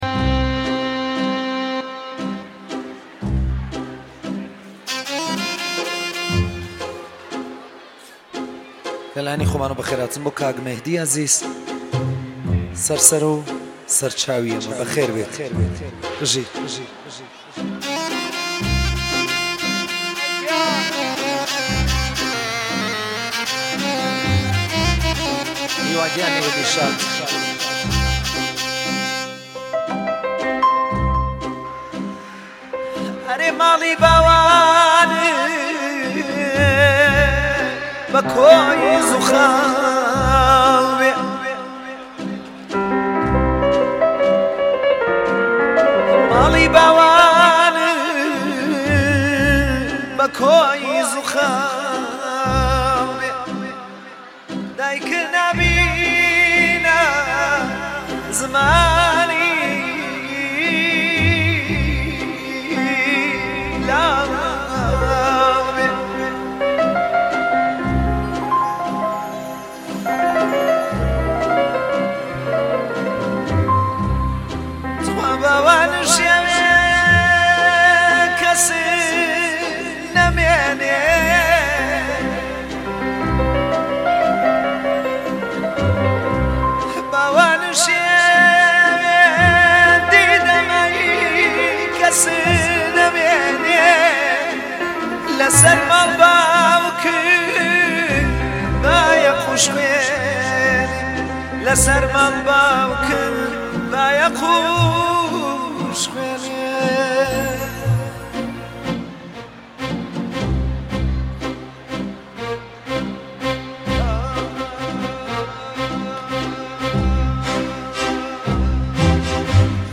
آهنگ کردی شاد